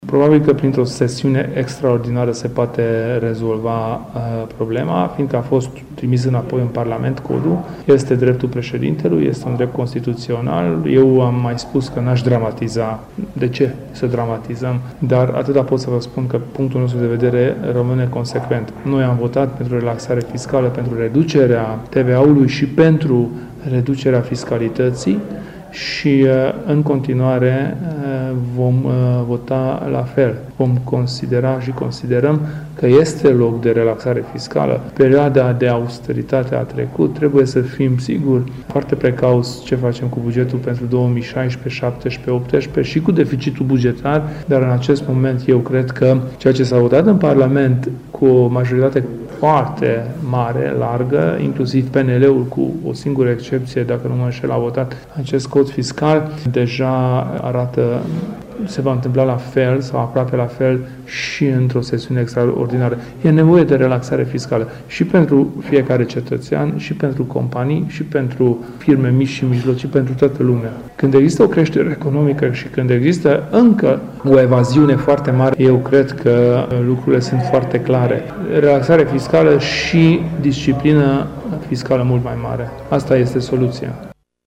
Declarația a fost făcută astăzi la Sfântu Gheorghe în cadrul unei întâlniri cu aleșii locali ai UDMR. Kelemen Hunor a reafirmat faptul că în România e nevoie de o relaxare fiscală, prin urmare parlamentarii Uniunii vor avea o pozitie constanta si vor vota noul Cod fiscal.